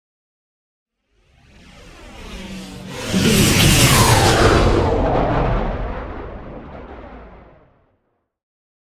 Whoosh large heavy
Sound Effects
dark
intense
whoosh